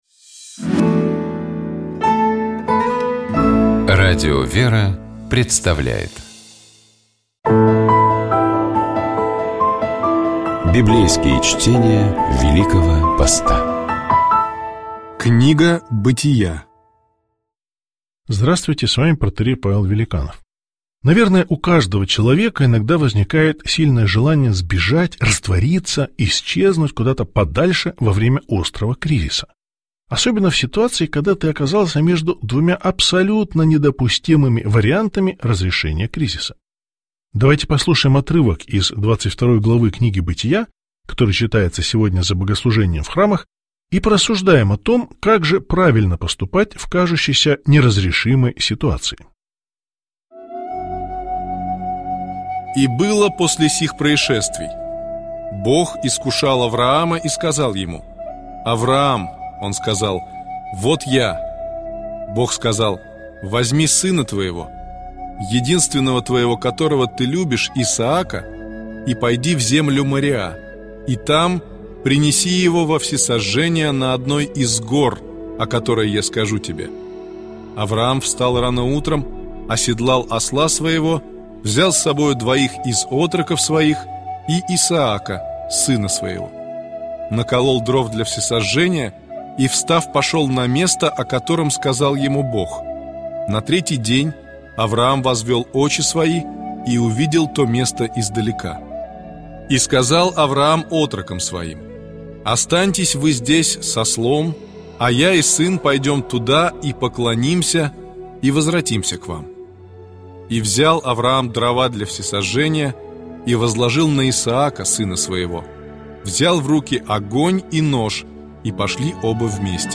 Библейские чтения
Читает и комментирует